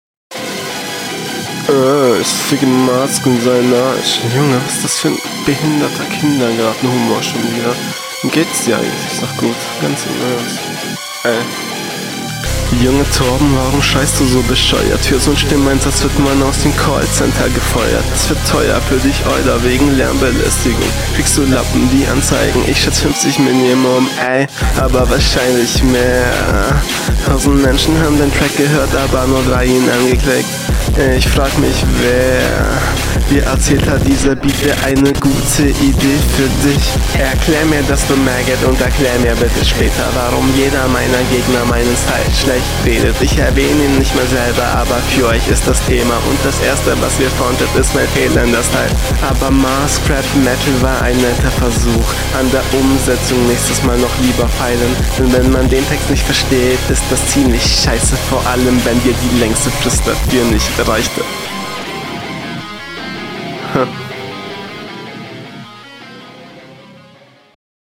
warum redest du so auf dem Beat?
komisch oder gar nicht gemischt und raptechnisch hast du auch stärkere sachen